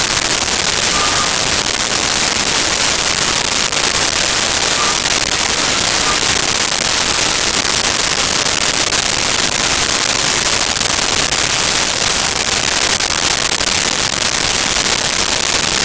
Family At Outdoor Restaurant Loop
Family at Outdoor Restaurant Loop.wav